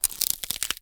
ALIEN_Insect_18_mono.wav